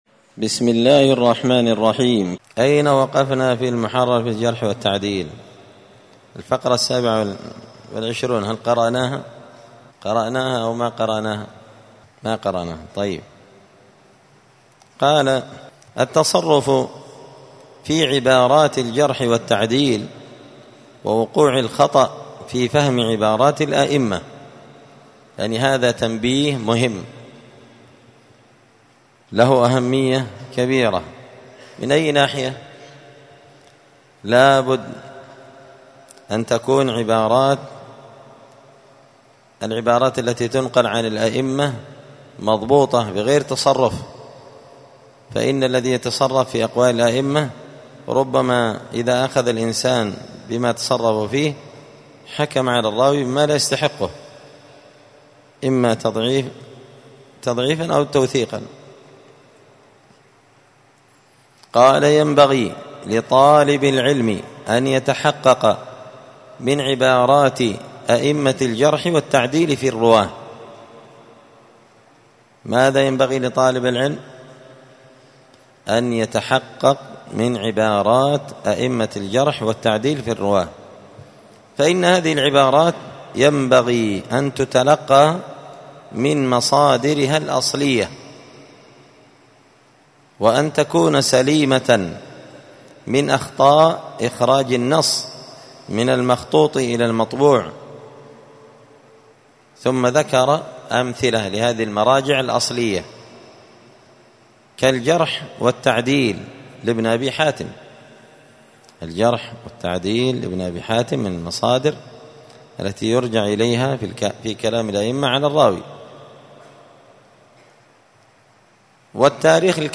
*الدرس السادس والعشرون (26) التصرف في عبارات الجرح والتعديل ووقوع الخطأ في فهم عبارات الأمة*